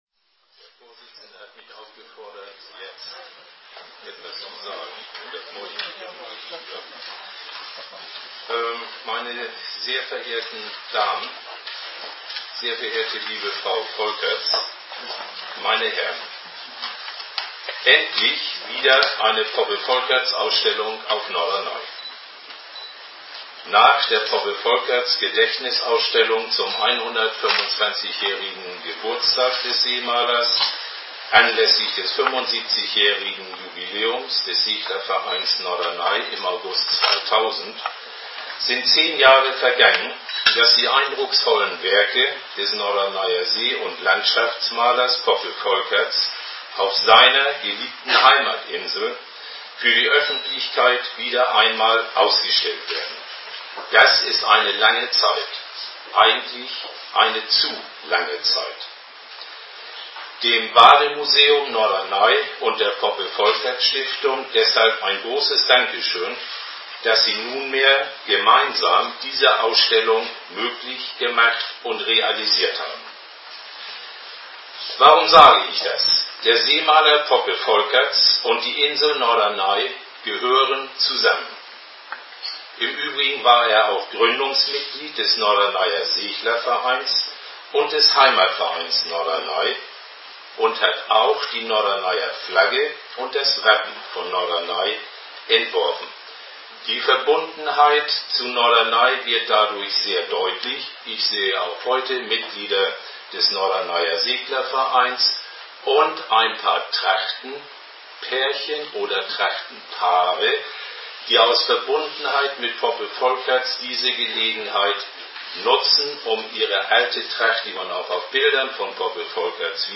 rede.mp3